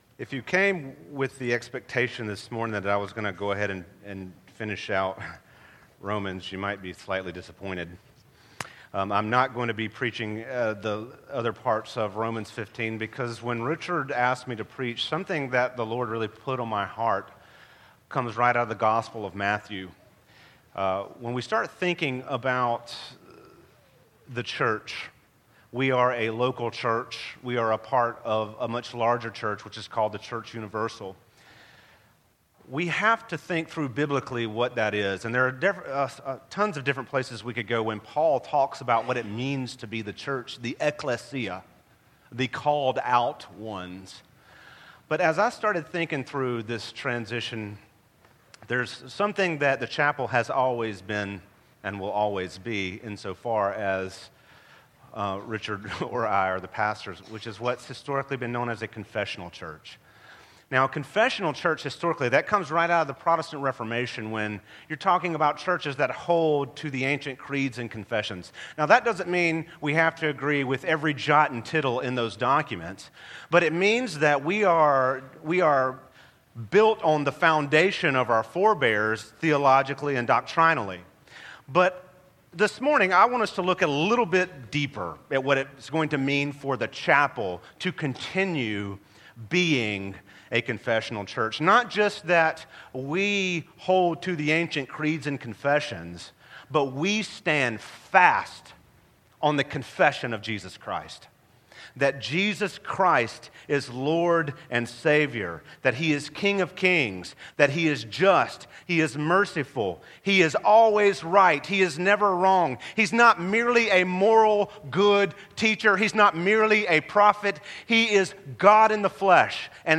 The Confessional Church » The Chapel Church of Gainesville, Florida